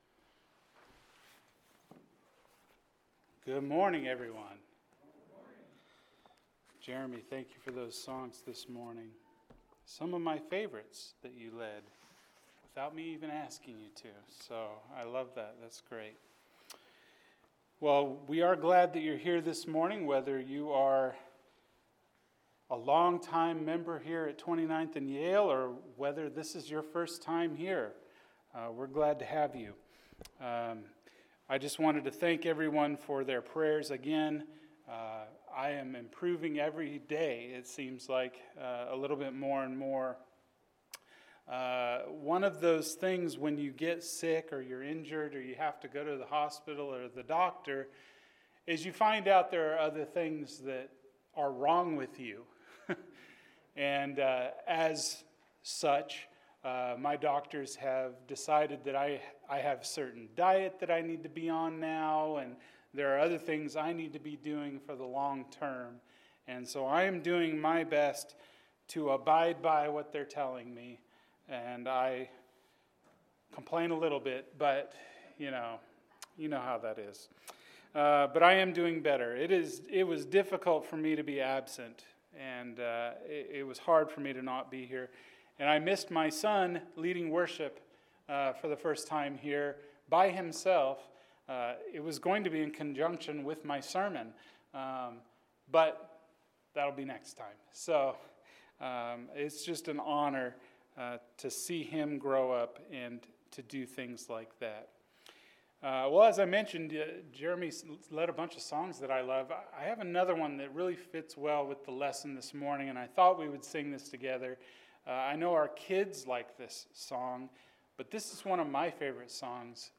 Is God Dead? – Sermon